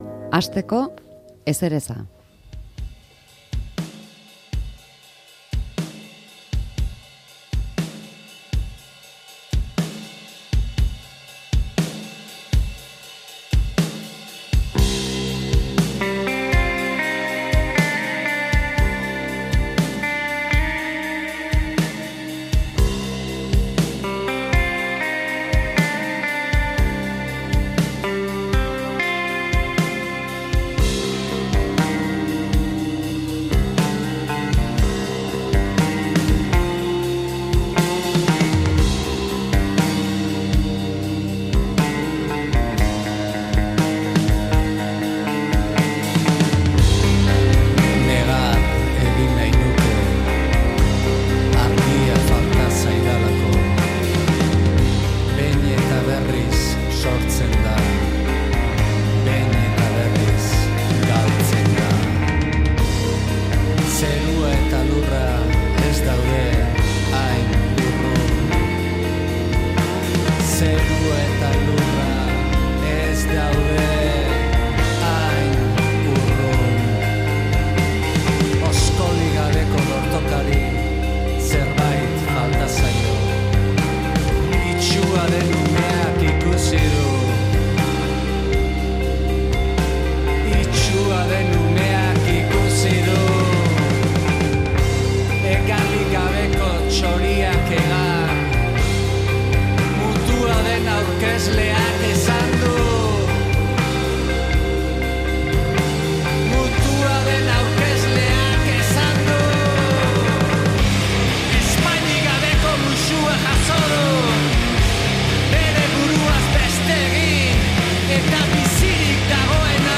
Musika iluna, hitz ilunak... garaiak eta egoerek hala eskatuta.